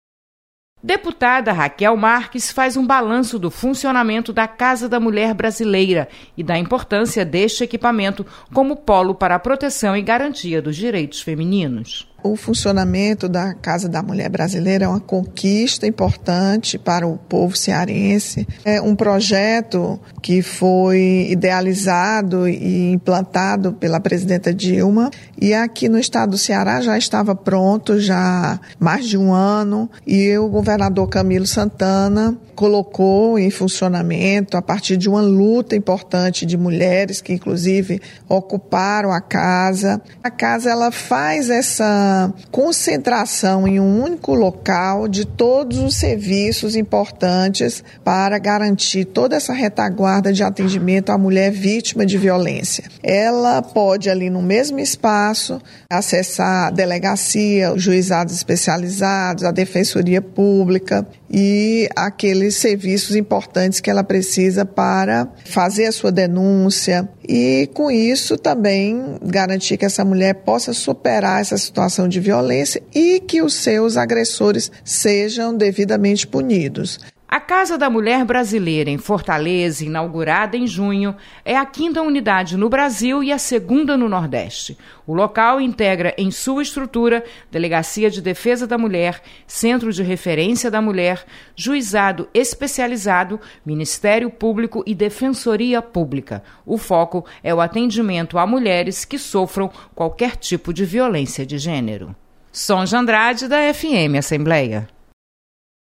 Deputada Rachel Marques destaca iniciativa que beneficia mulheres em situação de violência. Repórter